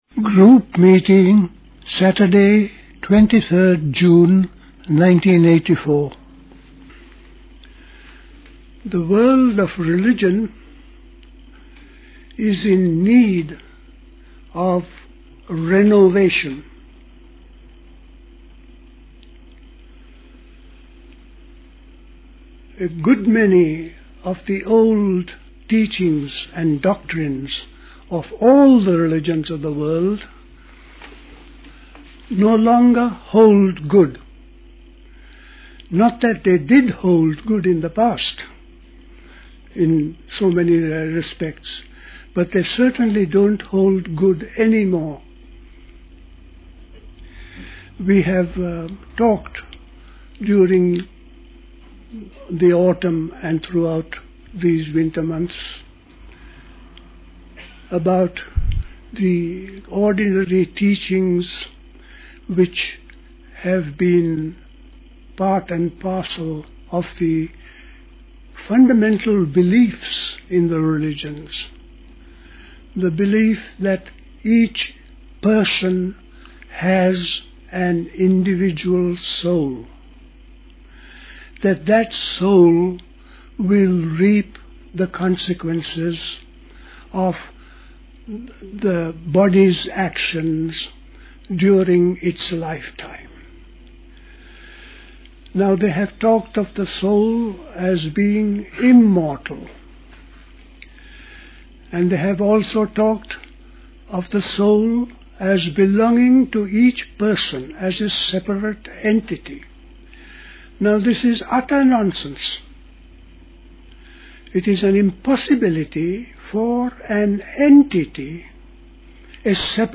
A talk